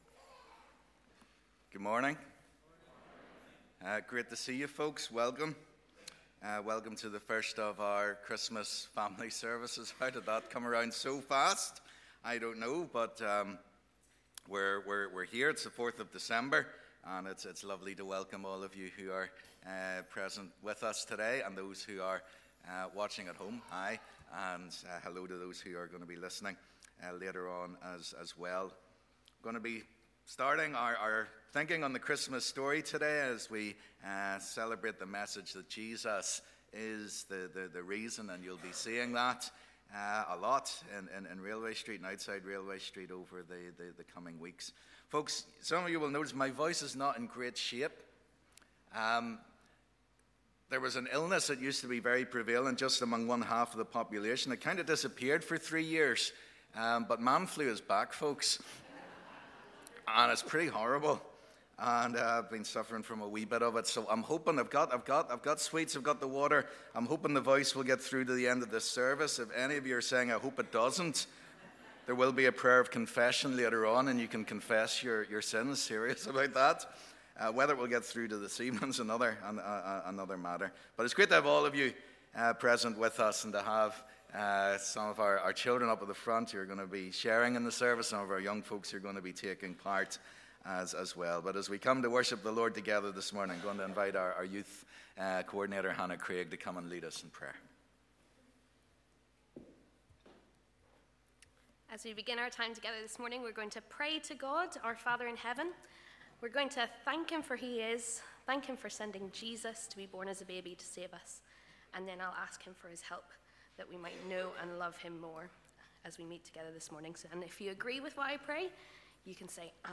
Welcome to our annual Christmas Gift Family Service.